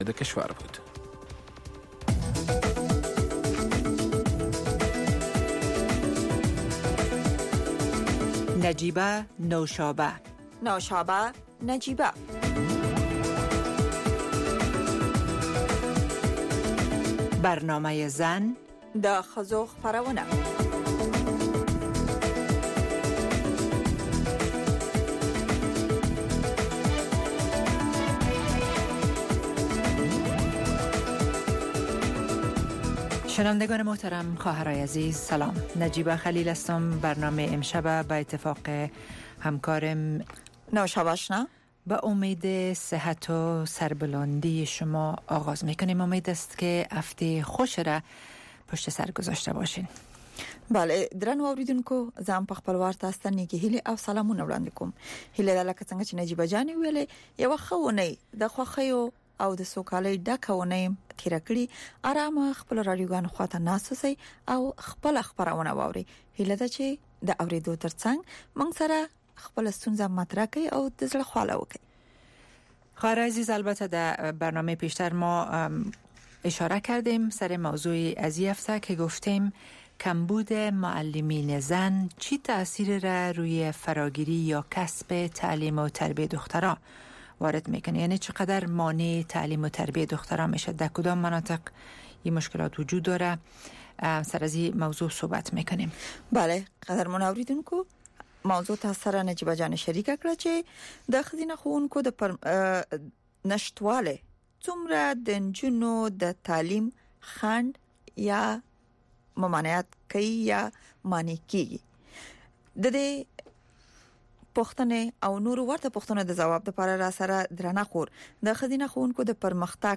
گفت و شنود - خبرې اترې، بحث رادیویی در ساعت ۰۸:۰۰ شب به وقت افغانستان به زبان های دری و پشتو است. در این برنامه، موضوعات مهم خبری هفته با حضور تحلیلگران و مقام های حکومت افغانستان به بحث گرفته می شود.